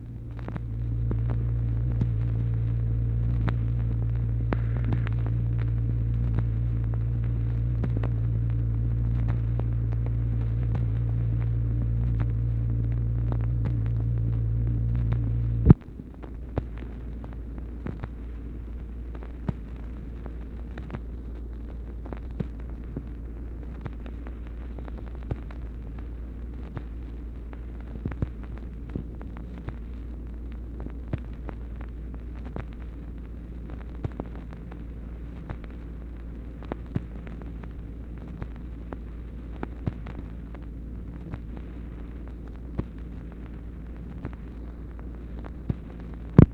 MACHINE NOISE, January 2, 1964